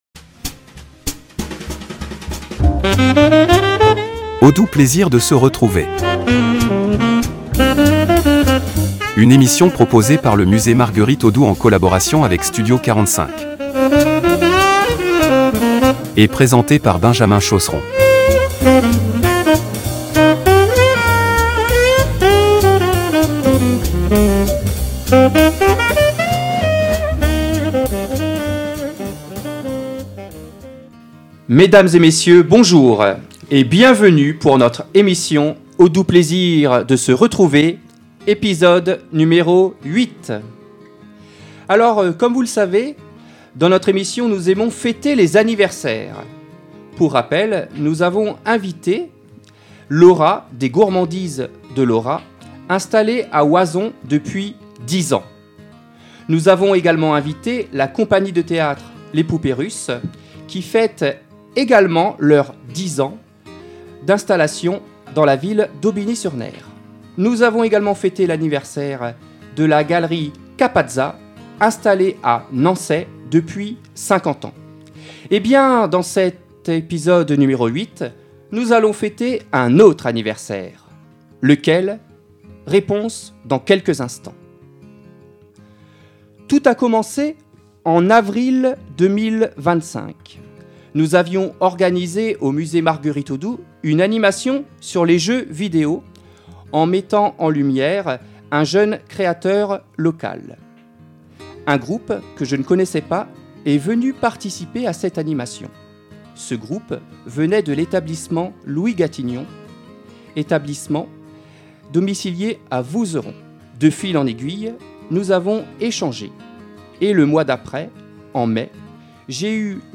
Directeurs, animateurs, stagiaires et artistes partagent leurs témoignages, leurs émotions et leur engagement autour d’un même objectif : favoriser l’inclusion, la créativité et l’épanouissement des personnes en situation de handicap.